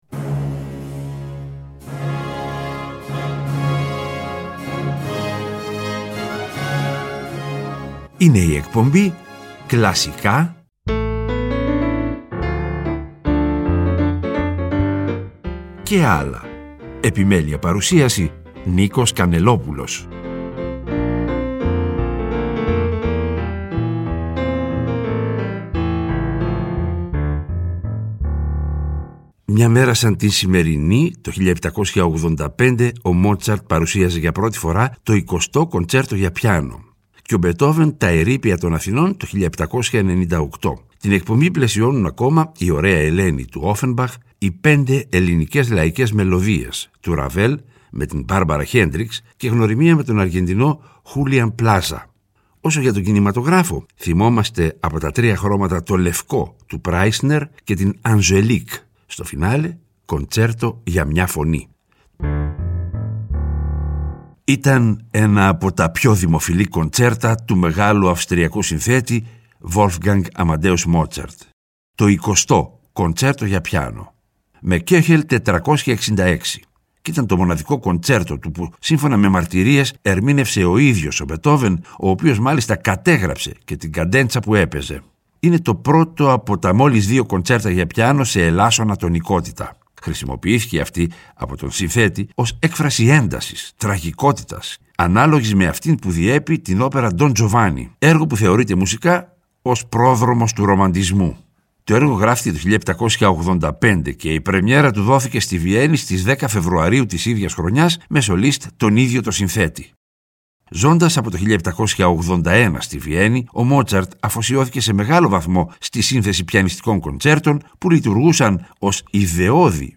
Λόγω της ημερομηνίας που έκαναν πρεμιέρα, ακούμε το 20ό Κοντσέρτο για Πιάνο του Μότσαρτ (10/2/1785) και τα «Ερείπια των Αθηνών» του Μπετόβεν.
«άλλα» μουσικά είδη, όπω ς μιούζικαλ, μουσική του κινηματογράφου -κατά προτίμηση σε συμφωνική μορφή- διασκευές και συγκριτικά ακούσματα.